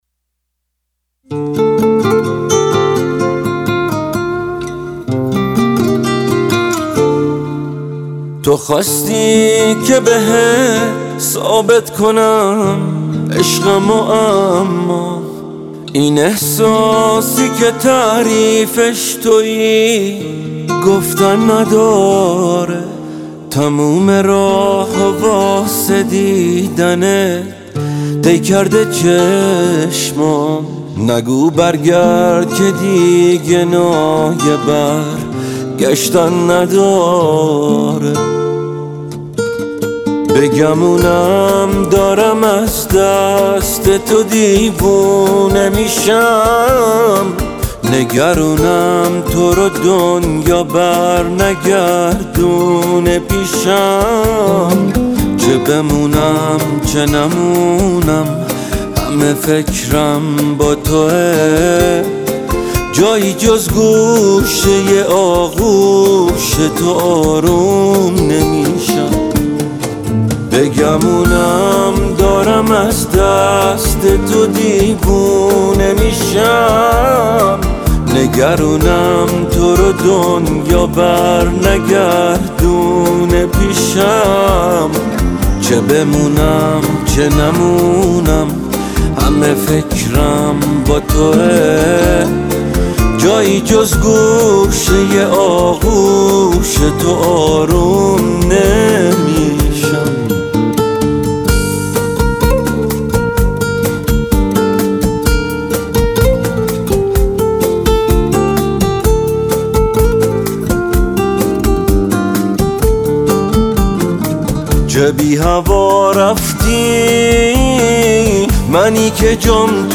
موسیقی پاپ ایران
پرانرژی
خواننده محبوب موسیقی پاپ ایرانی